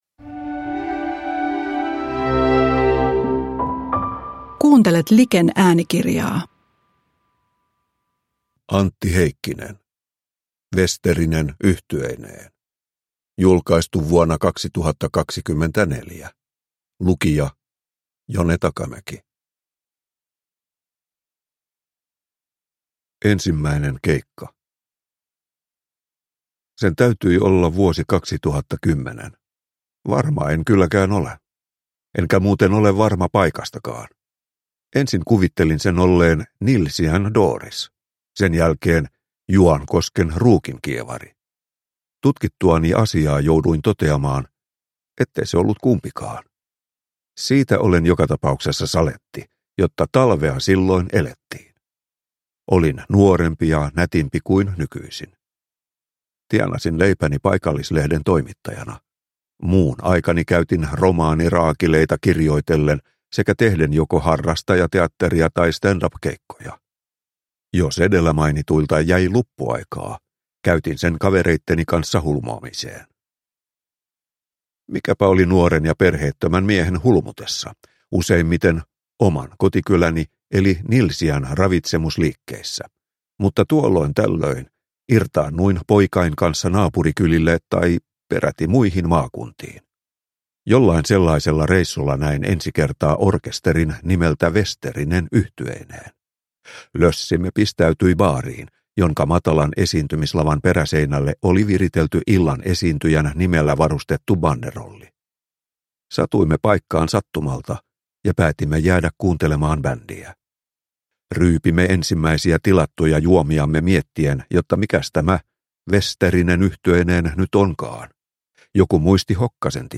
Vesterinen yhtyeineen (ljudbok) av Antti Heikkinen